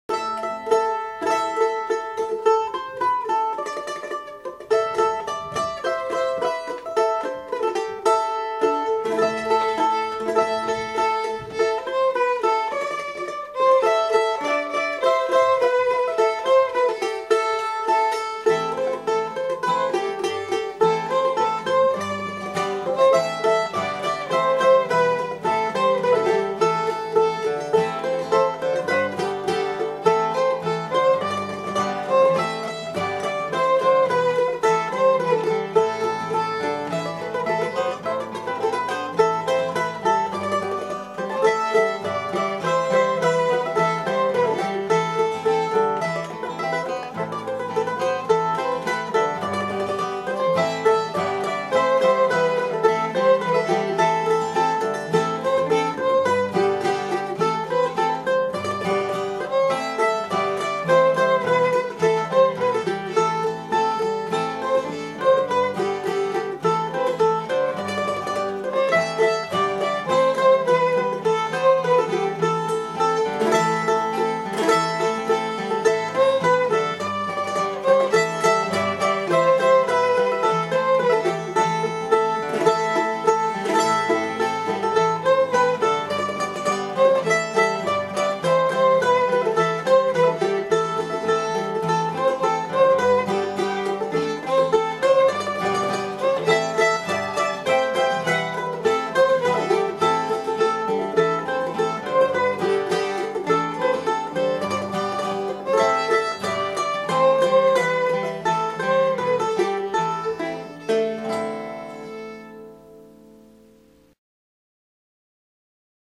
Recorded at Flying Fiddle Studio
Mandolin
Guitar
Bodhran